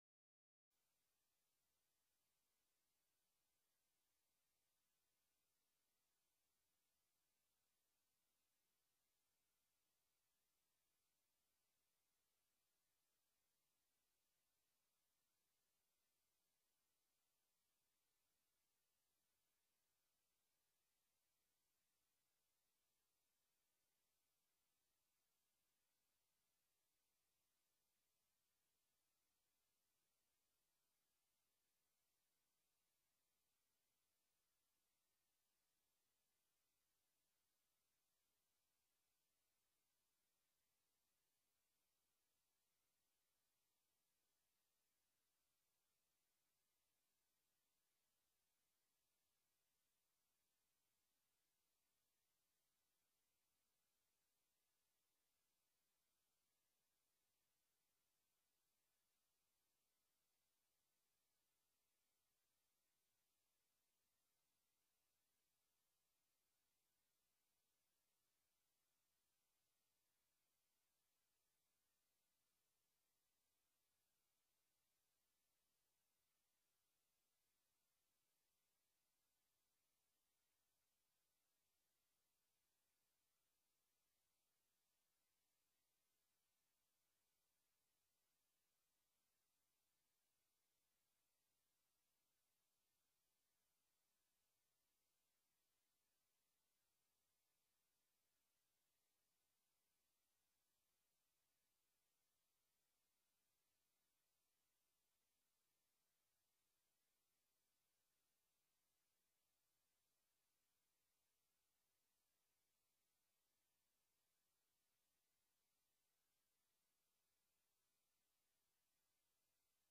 Vergadering 8 april 2020
Locatie: Raadzaal